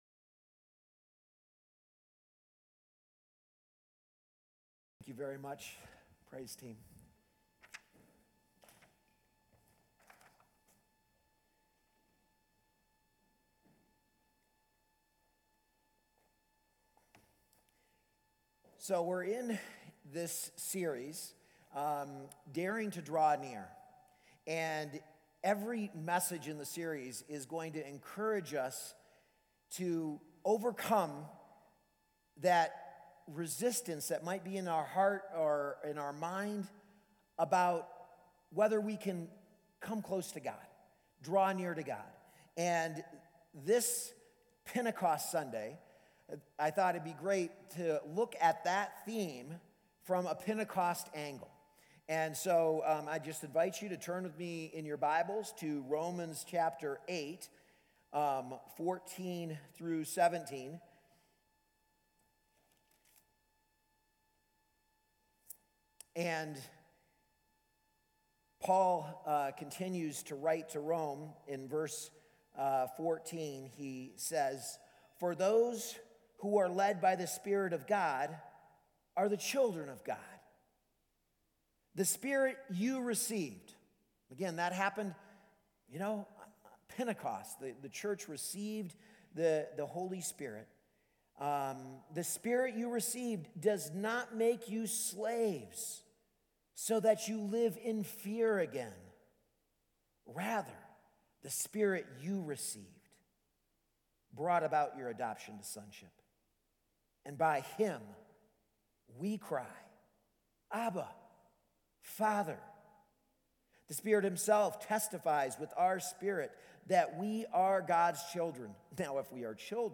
A message from the series "Daring to Draw Near."